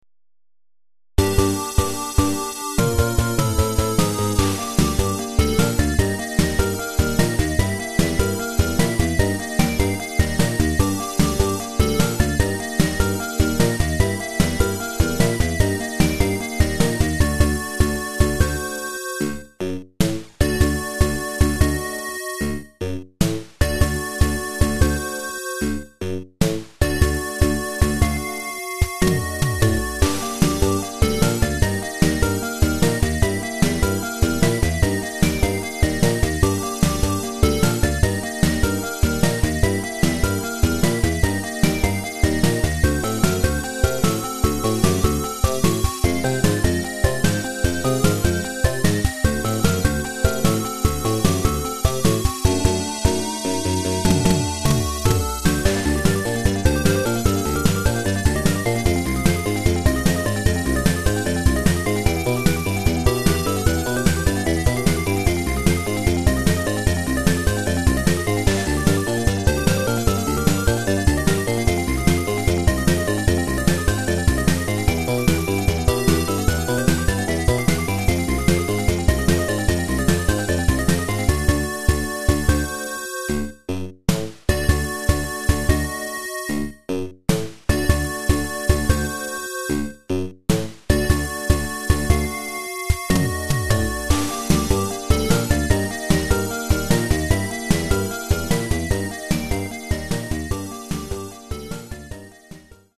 PSG